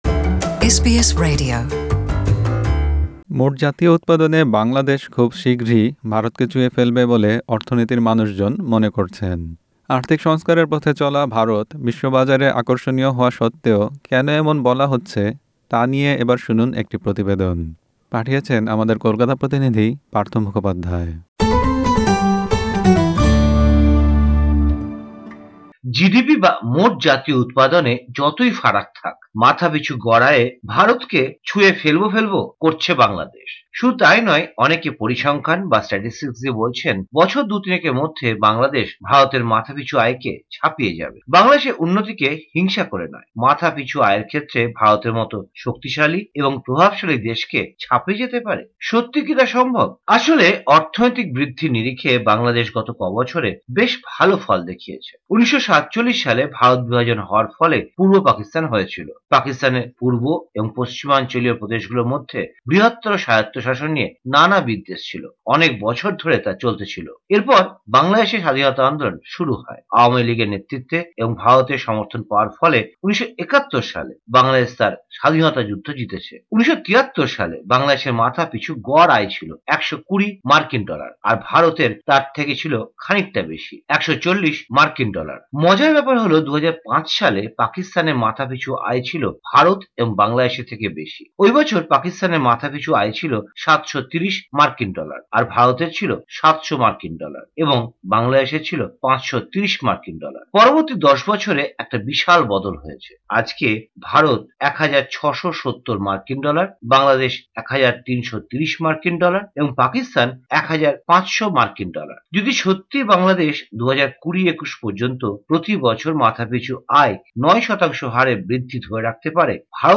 মোট জাতীয় উৎপাদনে বাংলাদেশ খুব শীঘ্রই ভারতকে ছুঁয়ে ফেলবে বলে অর্থনীতির মানুষজন মনে করছেন। আর্থিক সংস্কারের পথে চলা ভারত বিশ্ববাজারে আকর্ষণীয় হওয়া সত্ত্বেও কেন এমন বলা হচ্ছে, তা নিয়ে এবার শুনুন একটি প্রতিবেদন।